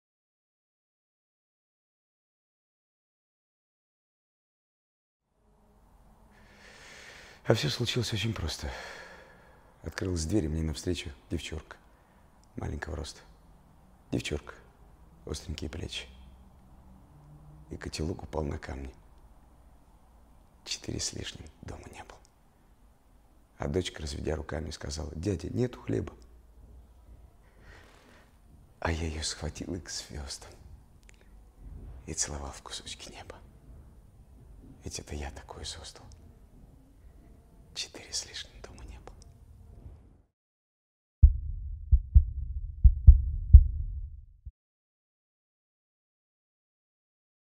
читает Сергей Безруков